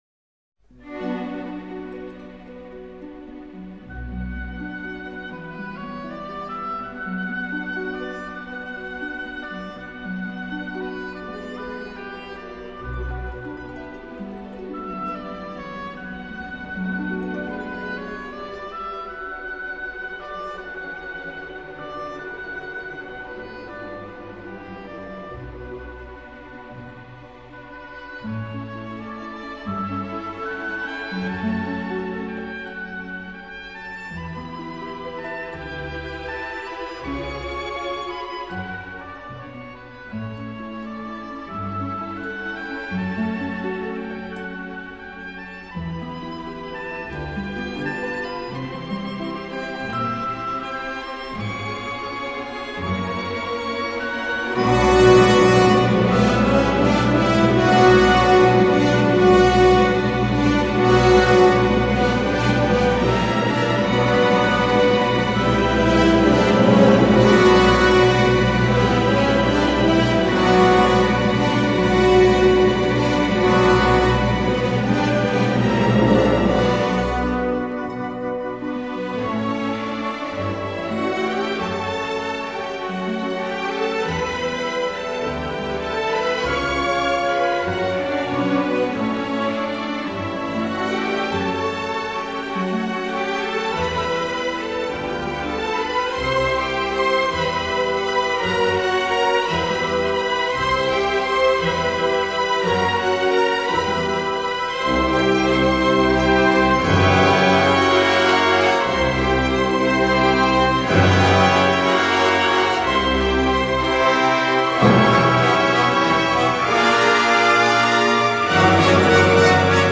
【纯音乐】